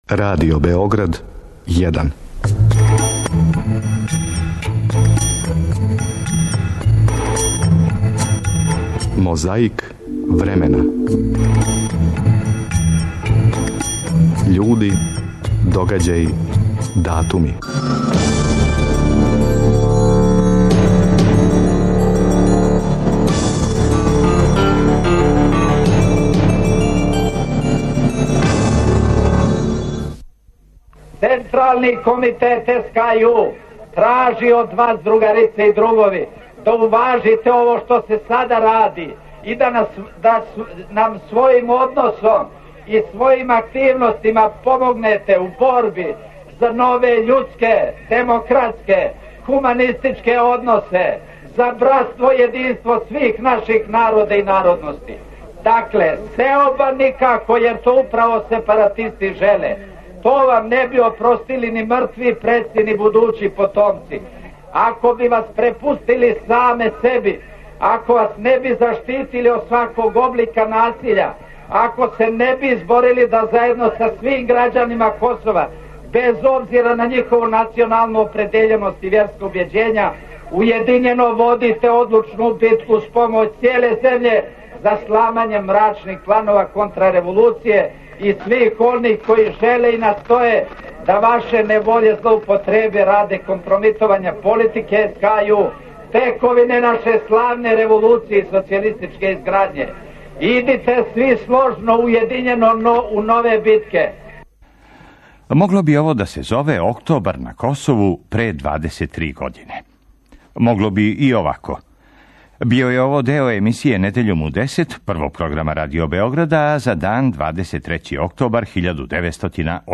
Том приликом саслушан је и "пресретнути" телефонски разговор из јула 1991. а између Слободана Милошевића и Радована Караџића.
У то име из Тонског архива Радио Београда позајмили смо траку на којој је забележено како чита одломке из лирске прозе.